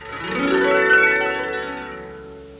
1 channel
Harp06.wav